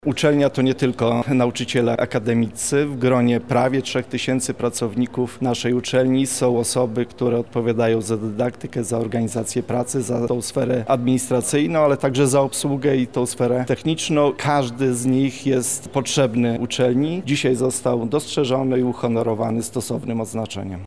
Wydarzenie odbyło się dzisiaj (29.11), o godzinie 12:00 w auli na Wydziale Prawa i Administracji UMCS.
– mówi prof. Radosław Dobrowolski, rektor Uniwersytetu Marii Curie-Skłodowskiej w Lublinie.